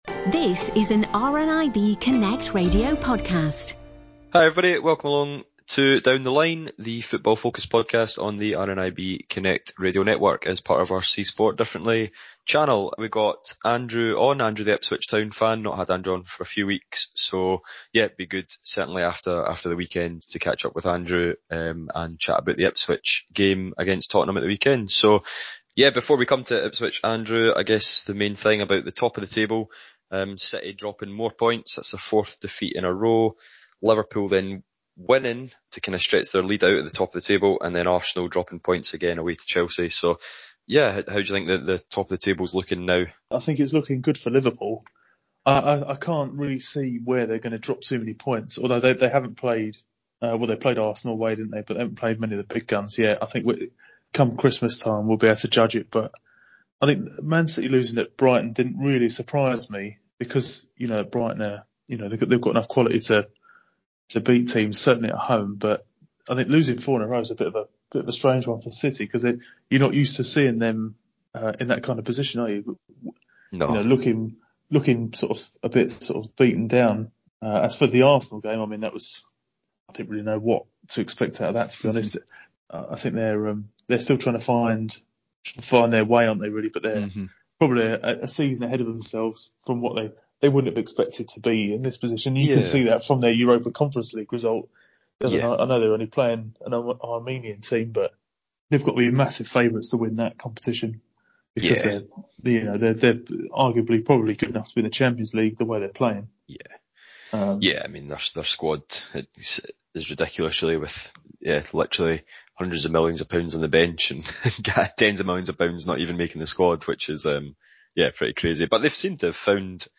Football-loving members of RNIB ‘Community Connections’ telephone groups get together each week to talk about the latest going on in leagues across Scotland and England. This time, looking back at a week of upsets and ahead to Nations League fixtures.